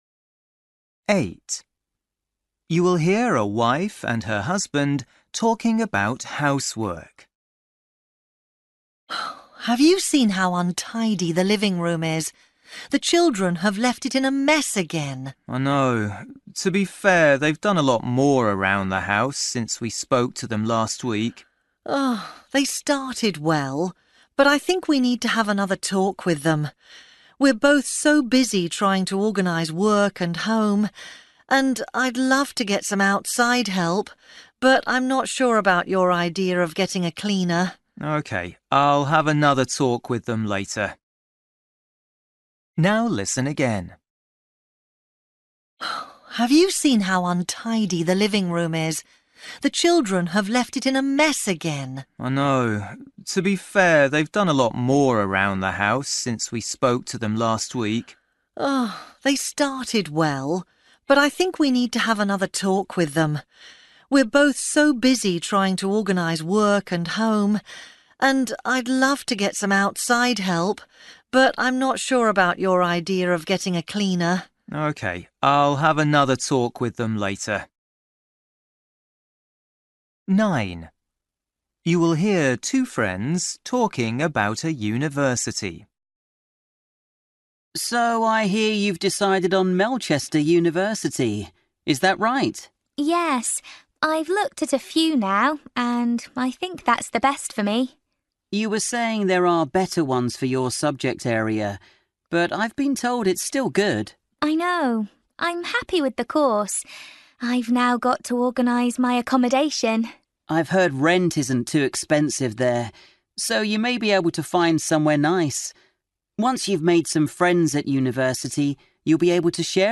Listening: everyday short conversations
You will hear a wife and her husband talking about housework.
You will hear two friends talking about a university.